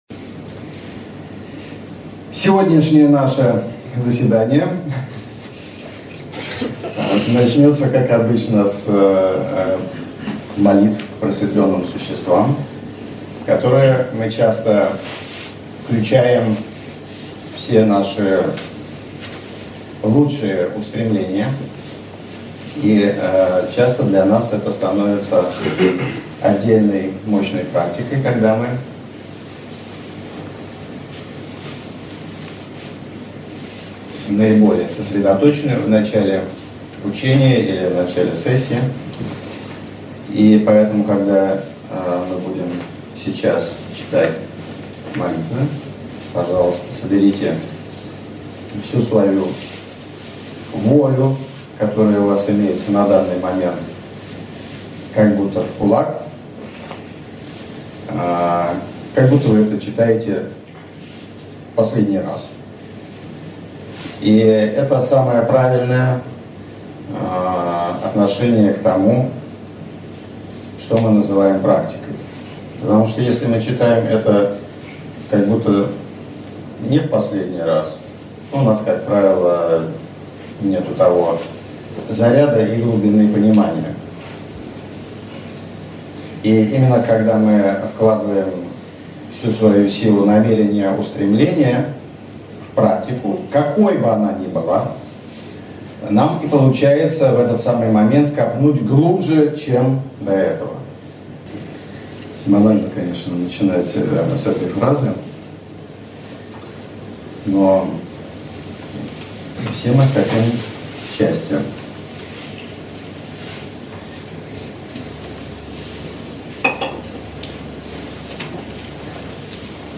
Аудиокнига Буддийская Тантра и принципы тантрической практики в современном понимании | Библиотека аудиокниг